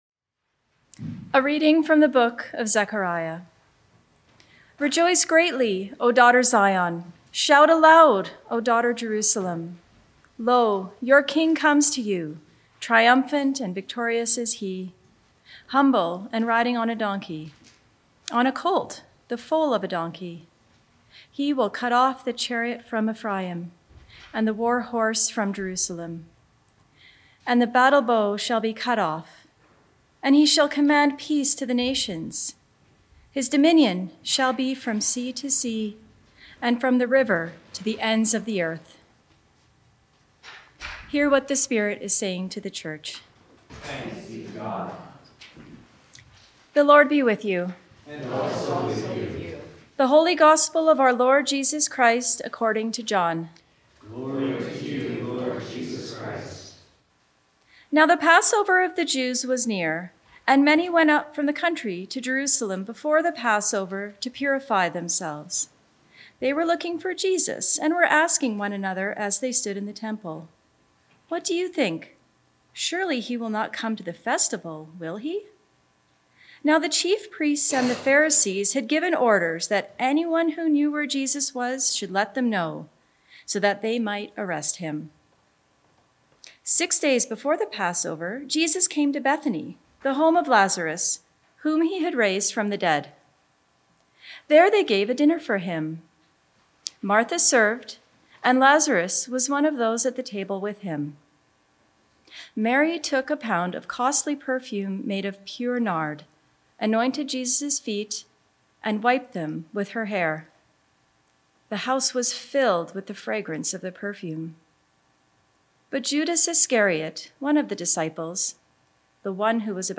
Sermons | The Church of the Good Shepherd